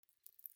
/ H｜バトル・武器・破壊 / H-15 ｜剣・刀1(生音寄り)